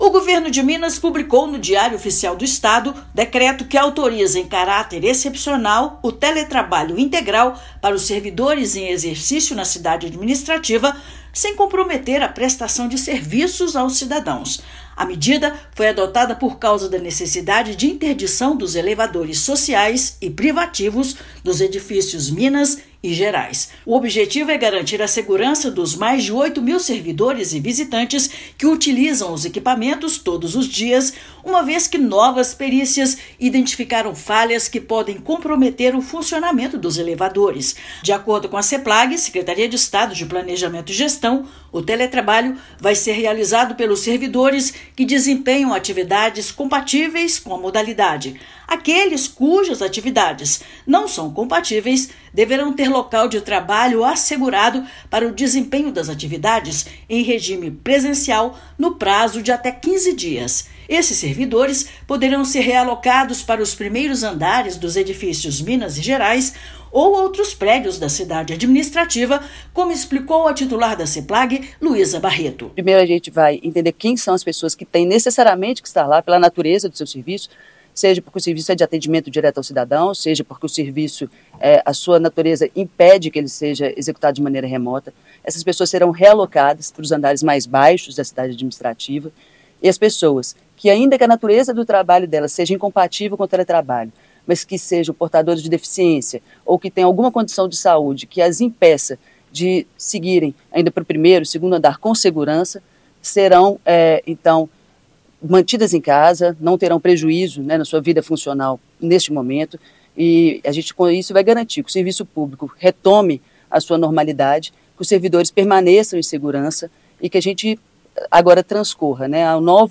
[RÁDIO] Governo de Minas regulamenta realização excepcional de teletrabalho por servidores da Cidade Administrativa
Decreto foi publicado de sábado (11/5) no Diário Oficial; medida está sendo adotada em razão da interdição dos elevadores do complexo. Ouça matéria de rádio.